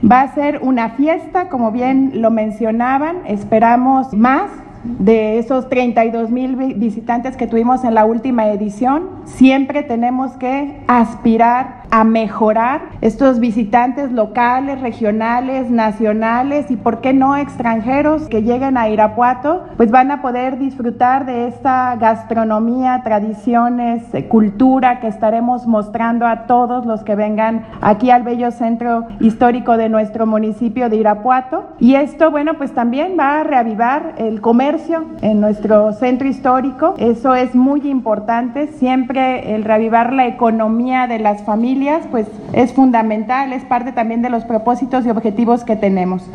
AudioBoletines
En rueda de prensa, la presidenta municipal, Lorena Alfaro García, informó que para esta edición se contará con la presentación del grupo ‘Viento y Sol’, para inaugurar el evento el viernes 7 de octubre a las 8:00 de la noche.
Lorena Alfaro García – Presidenta Municipal
Francisco Javier Valverde – Director de Inteligencia Turística del Estado de Guanajuato